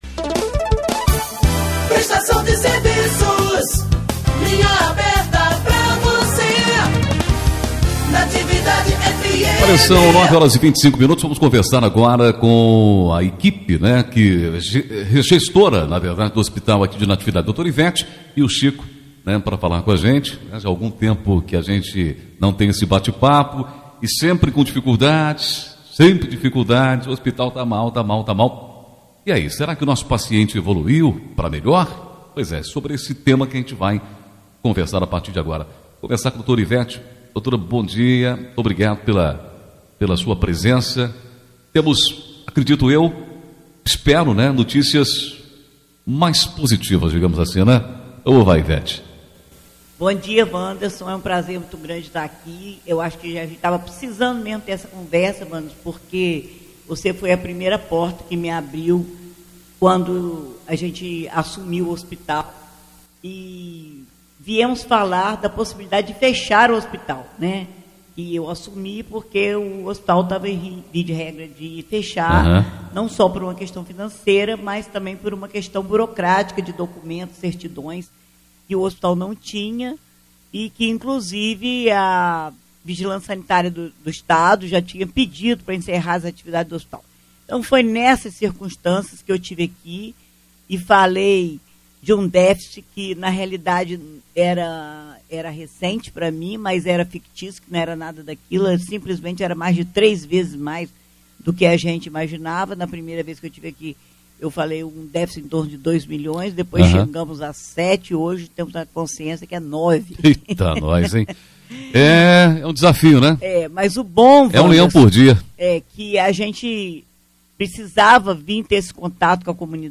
31 de outubro de 2024 DESTAQUE, ENTREVISTAS
ENTREVISTA-HOSPITAL.mp3